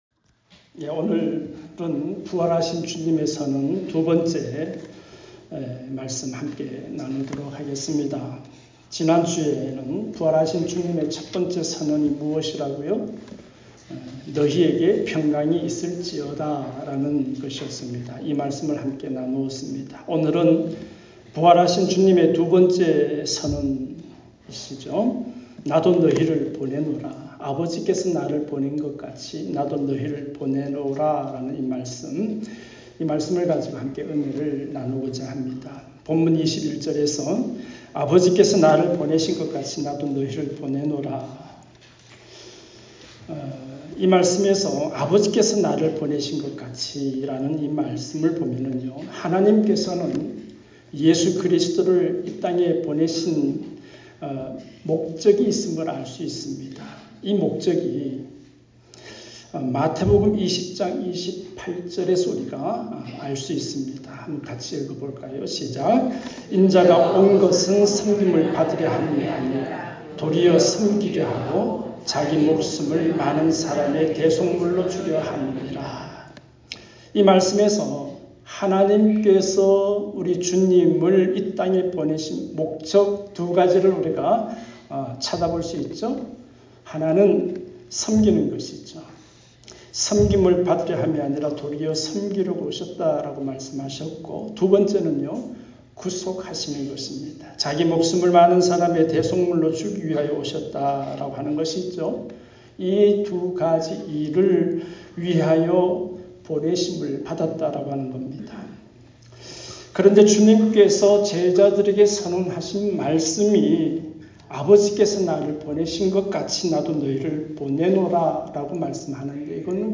주일음성설교 에 포함되어 있습니다.